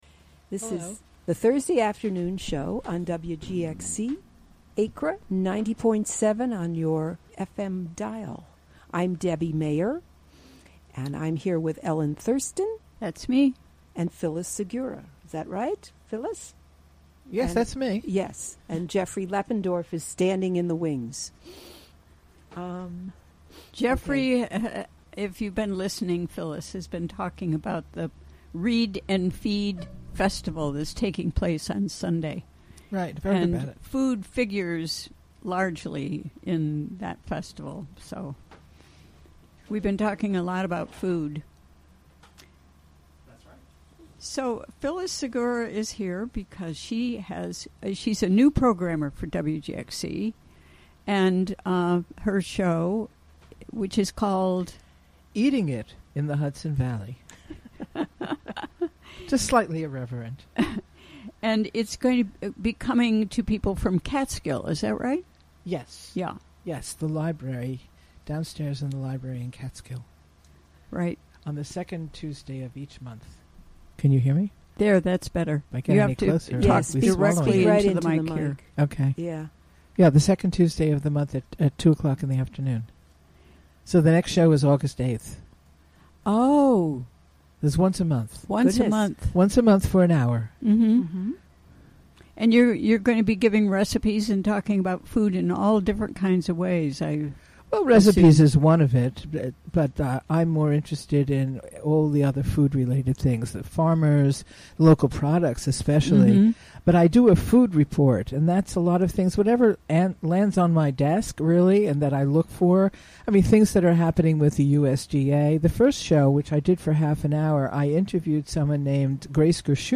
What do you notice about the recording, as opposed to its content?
Recorded during the WGXC Afternoon Show on Thursday, July 20, 2017.